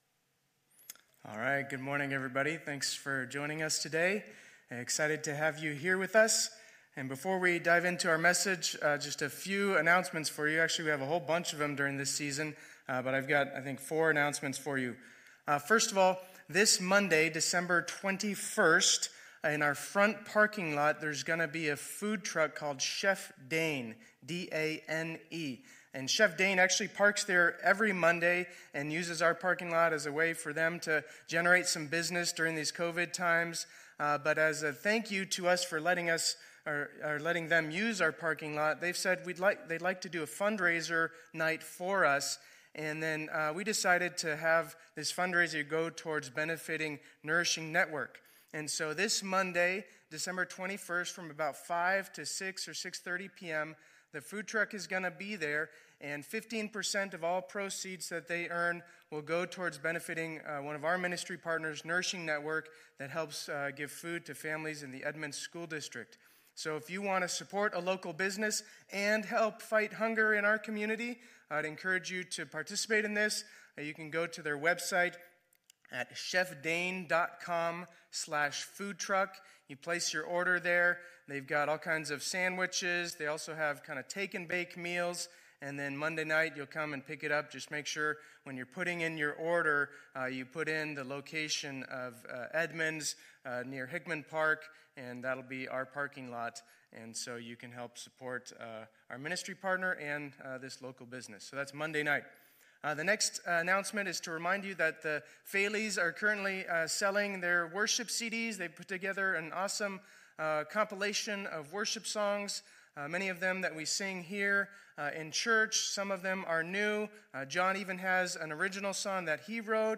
2020-12-20 Sunday Service